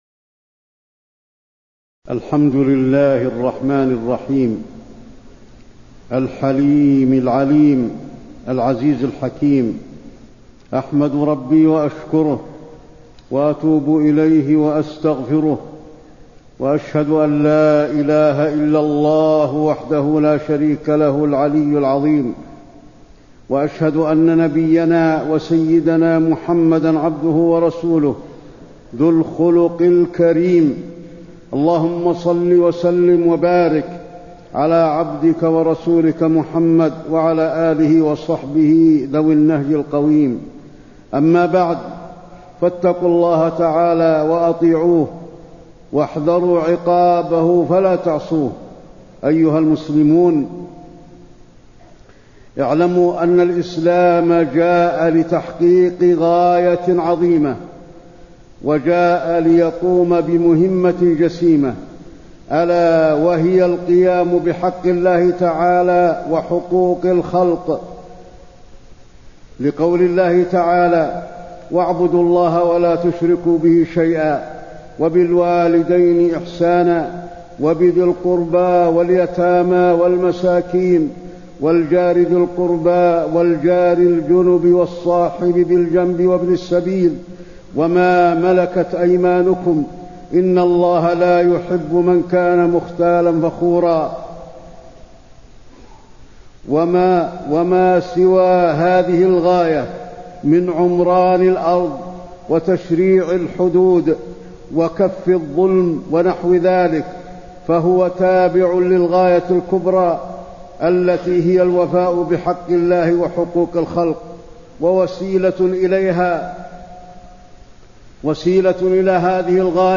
تاريخ النشر ٢٣ جمادى الآخرة ١٤٣٤ هـ المكان: المسجد النبوي الشيخ: فضيلة الشيخ د. علي بن عبدالرحمن الحذيفي فضيلة الشيخ د. علي بن عبدالرحمن الحذيفي حسن الخلق